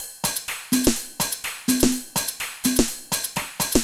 Index of /musicradar/retro-house-samples/Drum Loops
Beat 15 No Kick (125BPM).wav